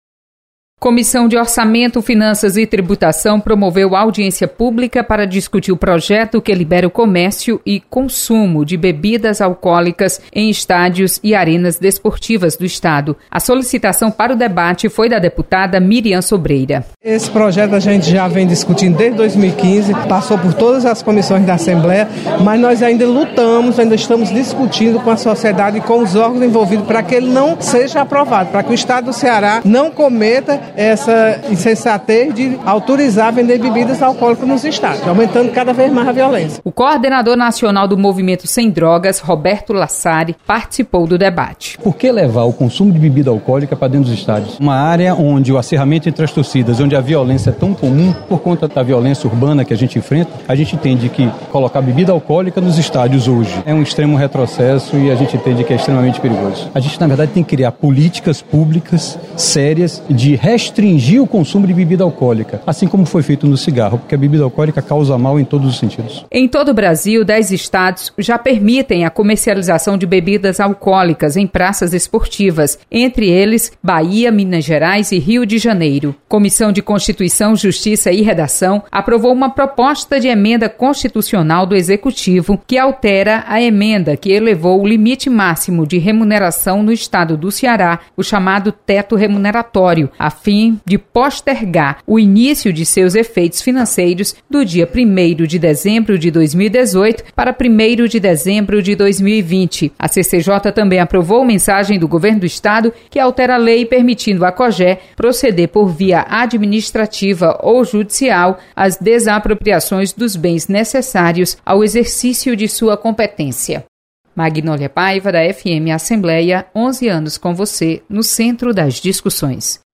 Acompanhe resumo das comissões técnicas permanentes da Assembleia. Repórter